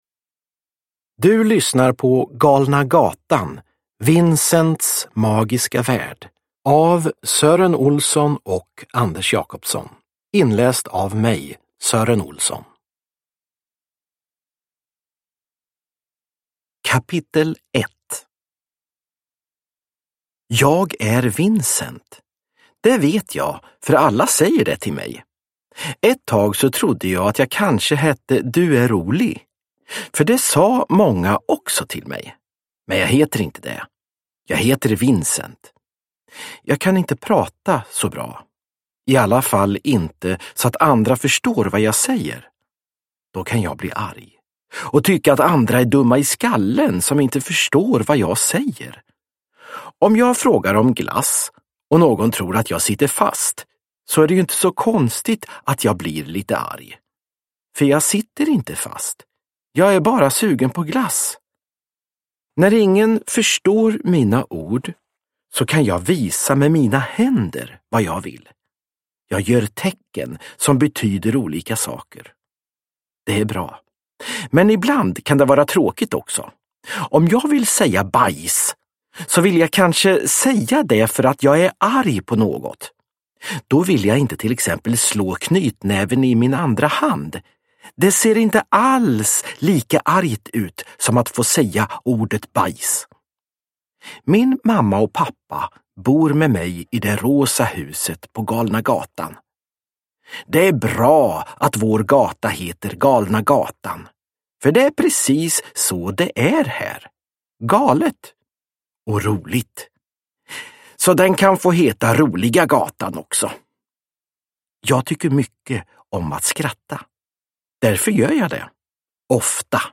Vincents magiska värld – Ljudbok – Laddas ner
Uppläsare: Sören Olsson